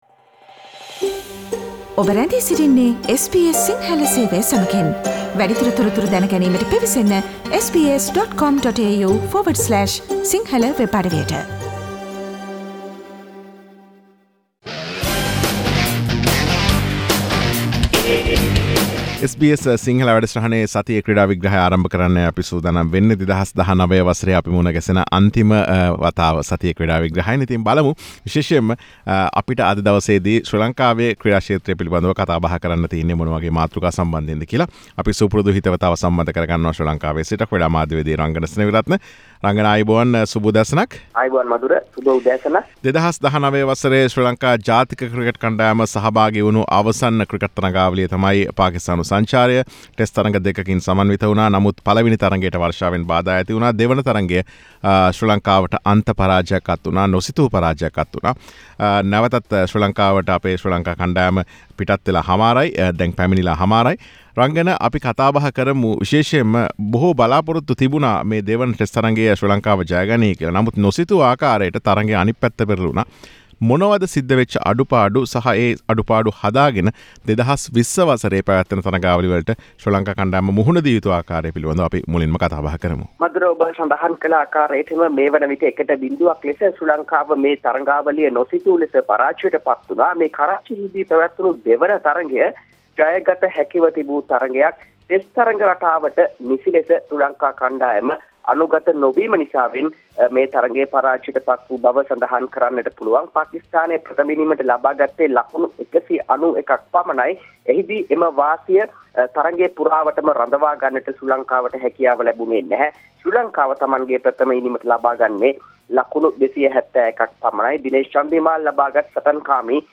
SBS Sinhalese Sports Wrap